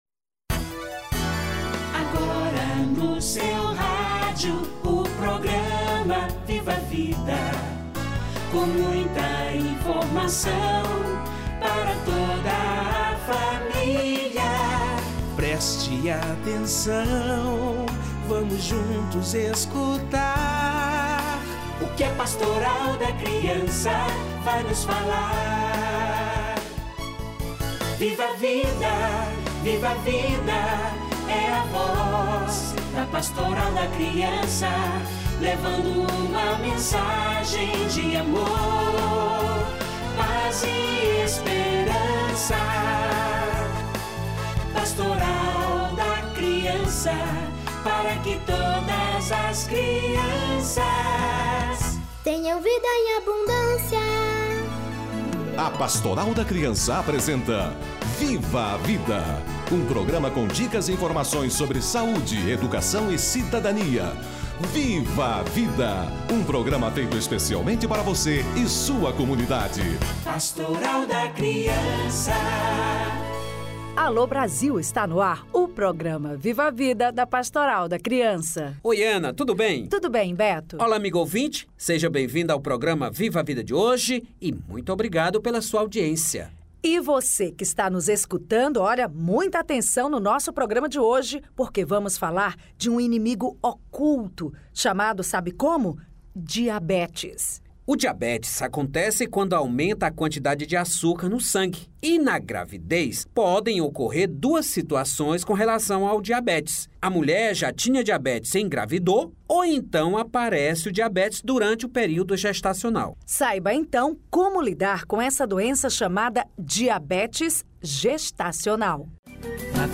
Diabetes gestacional - Entrevista